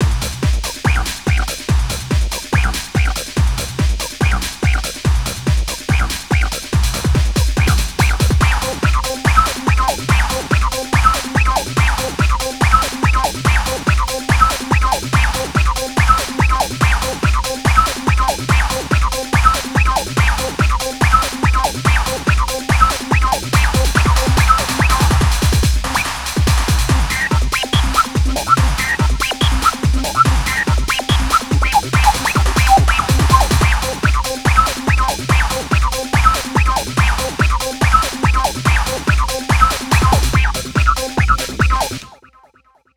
C'est de la Goa pure, avec une touche de Jungle, très soft
142 BPM